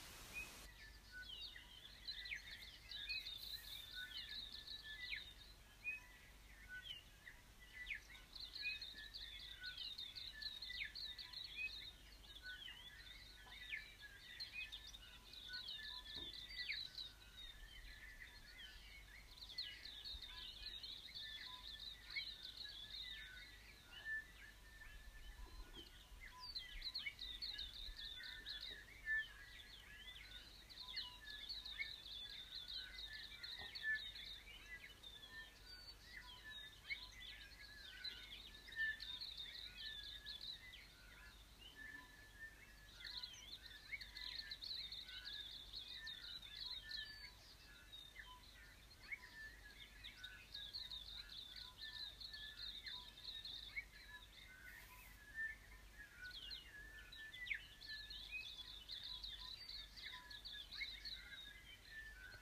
The sounds of birds were all around us making the morning entirely peaceful.
Akaroa-Birds.m4a